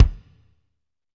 Index of /90_sSampleCDs/Northstar - Drumscapes Roland/DRM_Pop_Country/KIK_P_C Kicks x